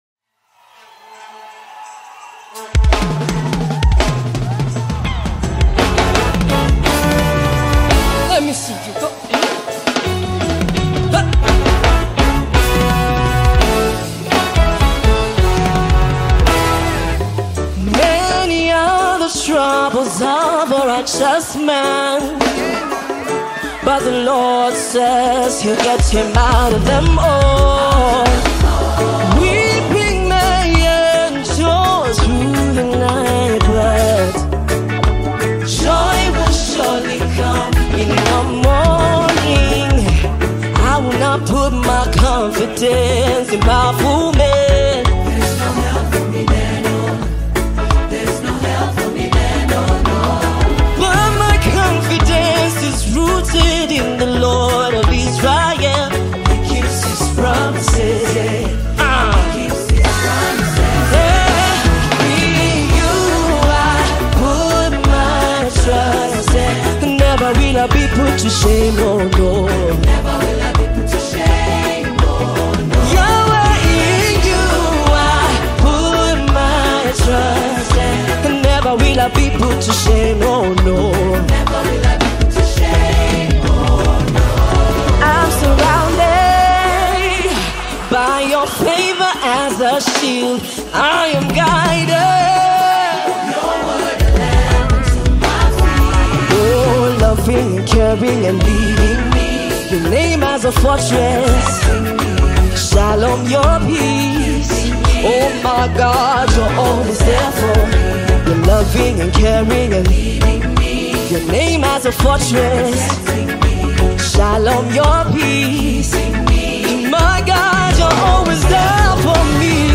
Gospel music track
Kenyan gospel artist